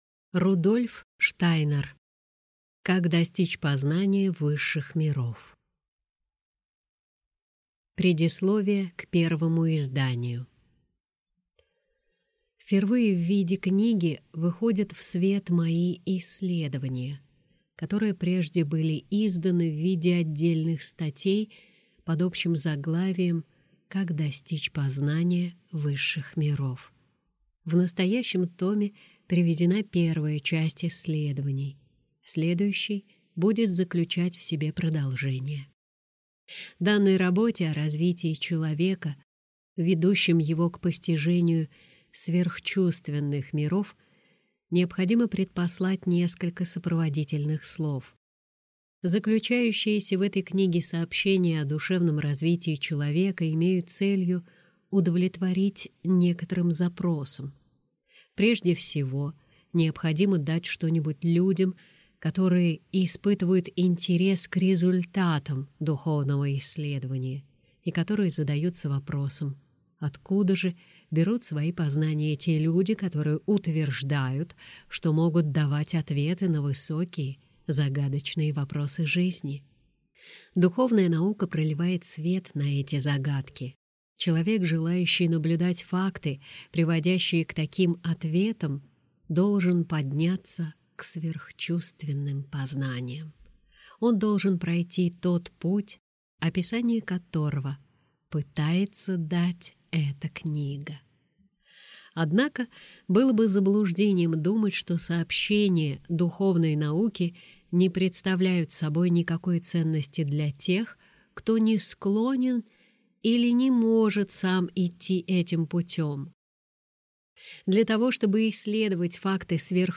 Аудиокнига Как достичь познания высших миров?